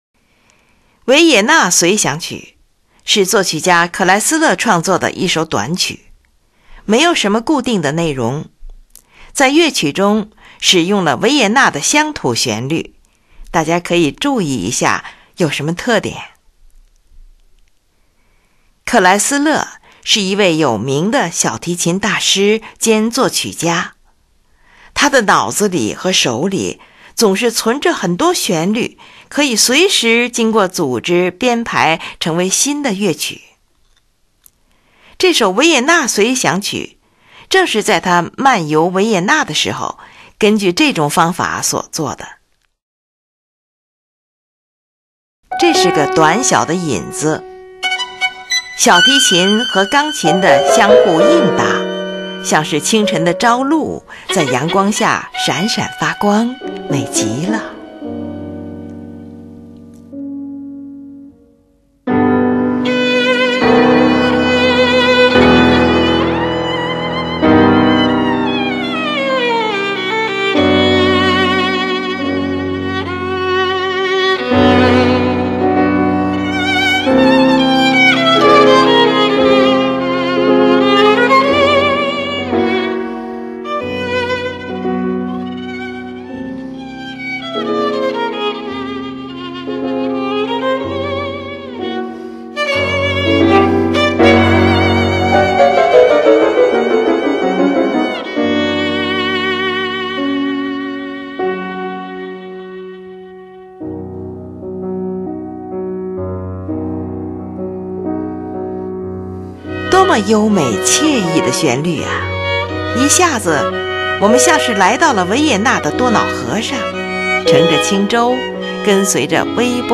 较行板慢的速度，B大调，徐缓的圆舞曲